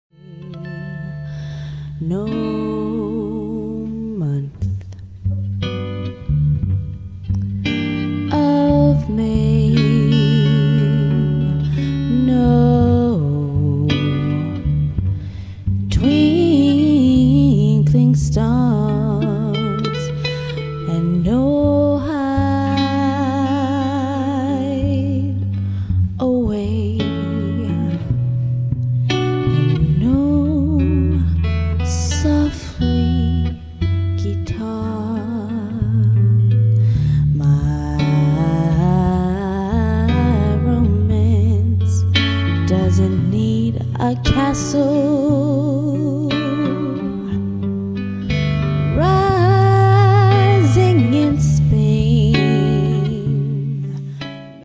en duo guitare-voix.